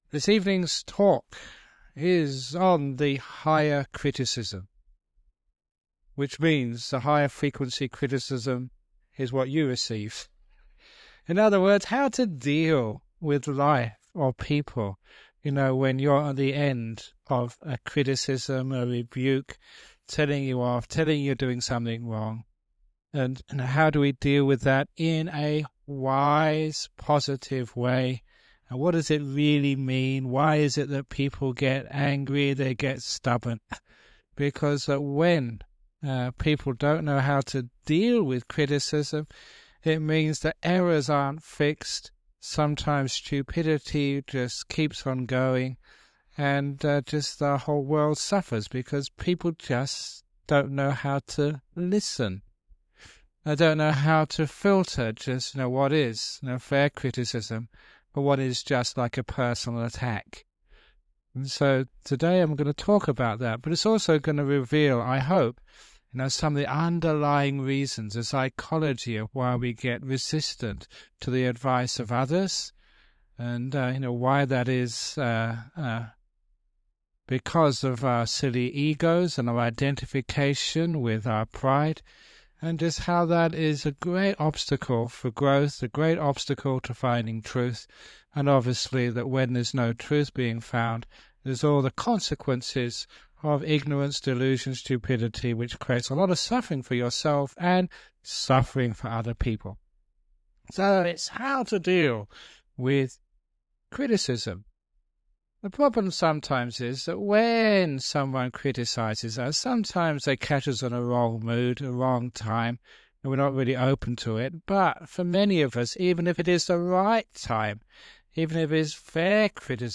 This talk is about how to deal with criticism in a wise and positive way, and why people often react negatively to criticism. We tend to take criticism personally because we identify with our ideas, views, and sense of self.